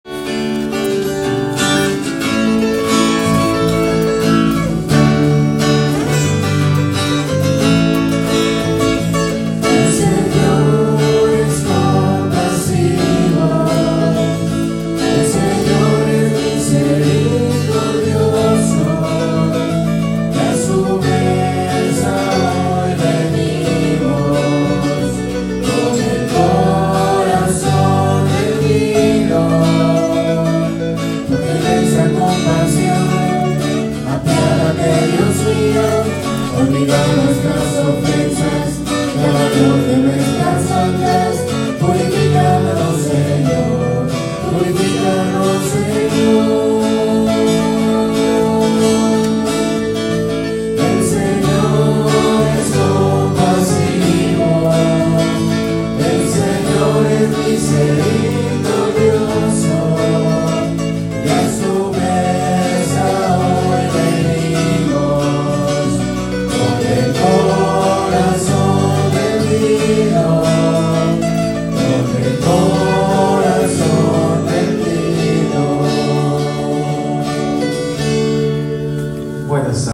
Tempo: 87